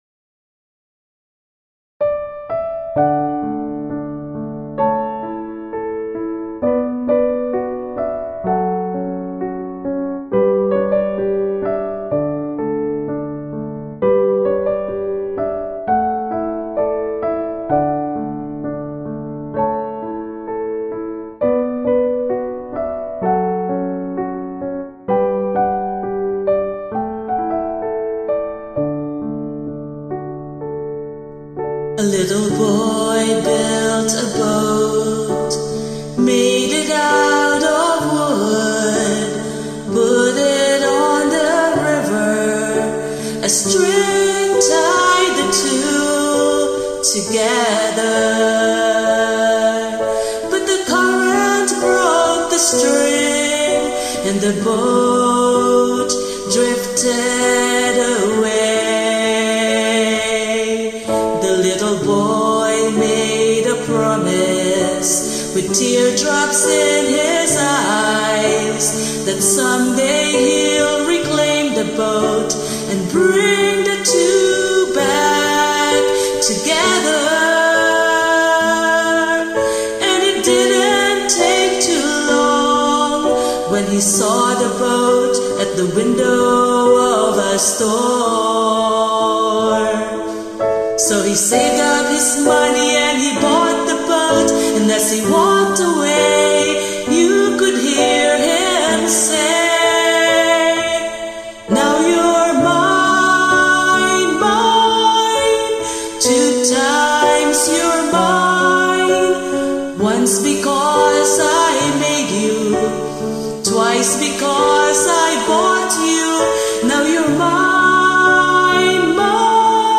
Hymns and Praise